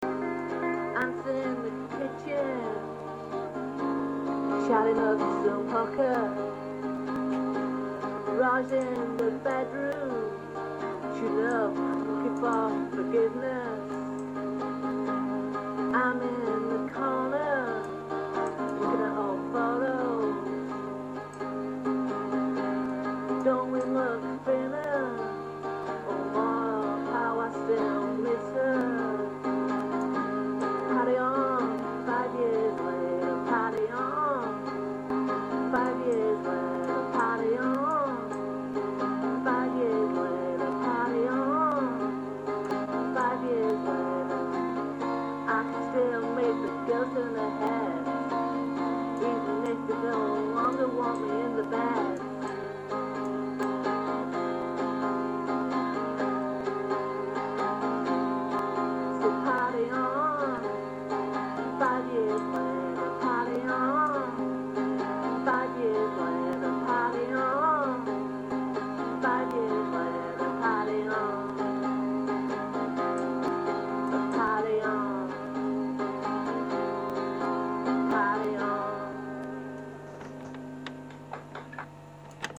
Raw-songs
Country
Folk